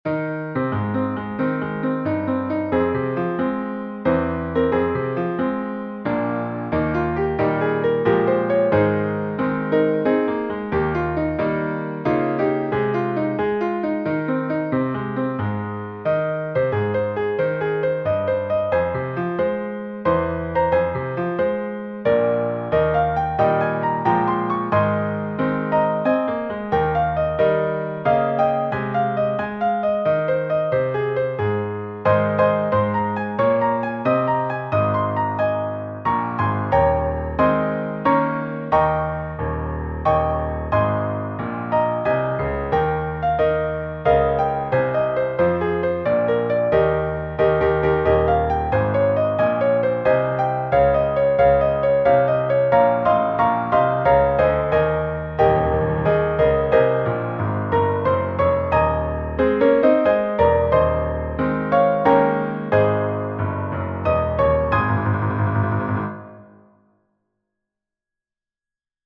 Voicing/Instrumentation: Piano Solo
Patriotic